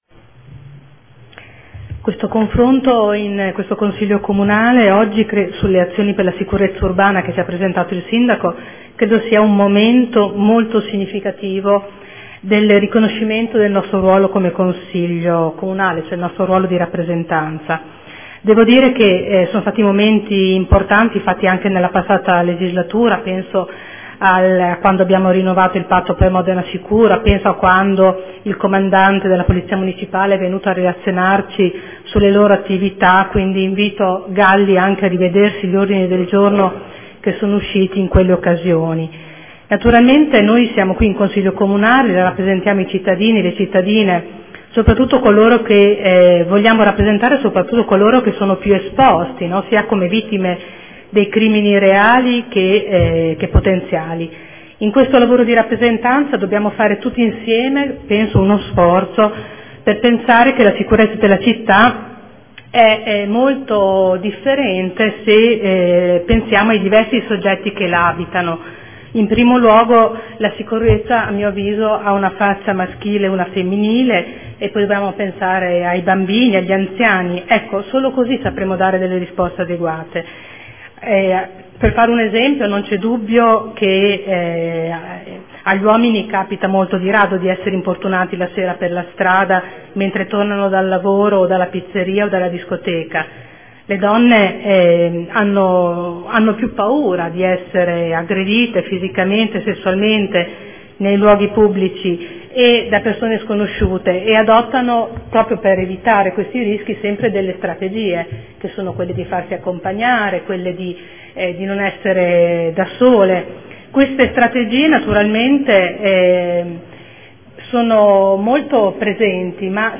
Seduta del 9/10/2014 Dibattito Sicurezza.